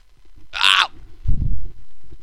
Epic Noise - AOH
Category: Sound FX   Right: Personal